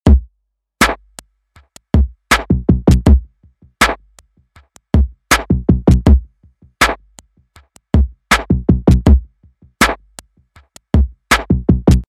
Zuviel Abwechslung führt aber vom Geradeaus-Feeling, das ich hier haben möchte, weg, weshalb ich mich auf ein einfaches Playback eines Loops ohne weitere Automation beschränke.